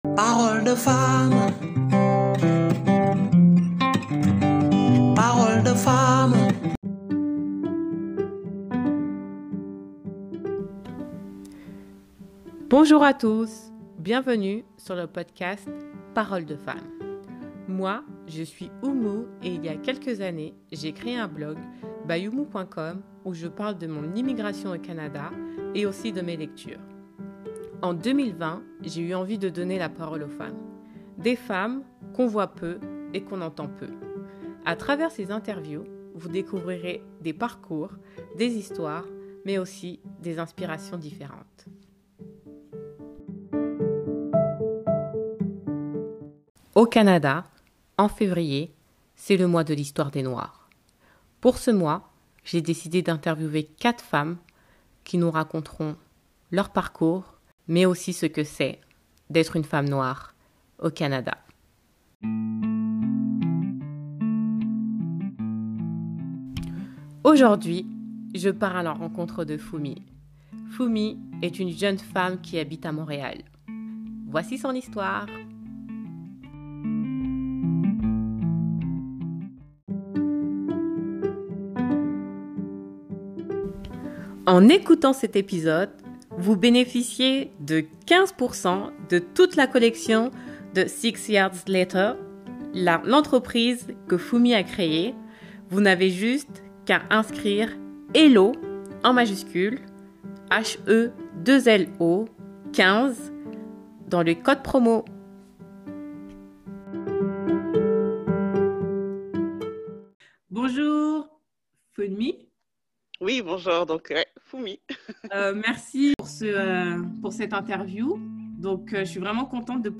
Chaque semaine en février, pendant le mois de l’histoire des Noirs, je reçois sur mon podcast Paroles de femmes une femme noire québécoise qui viendra nous partager son parcours et sa réalité…